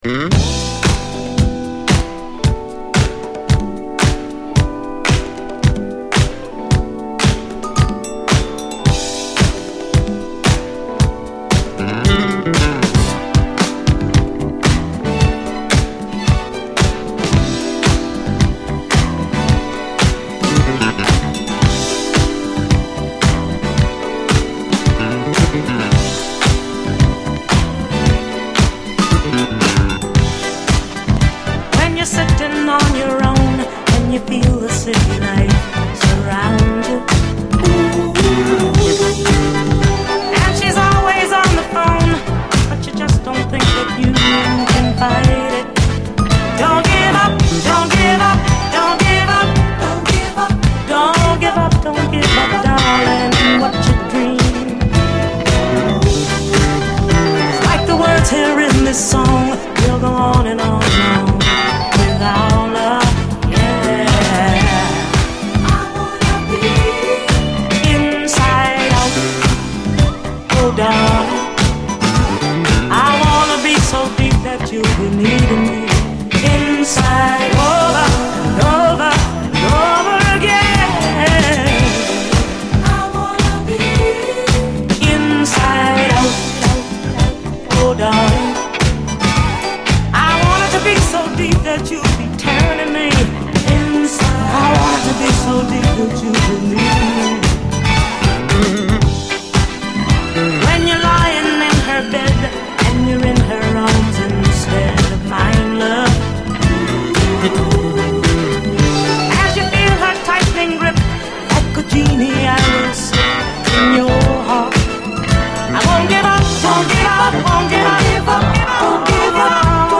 SOUL, 70's～ SOUL, DISCO
一級の都会的なメロウネス！
スムースなイントロから一気に引き込まれる、甘く切なくハートを揺らす永遠のダンス・クラシック！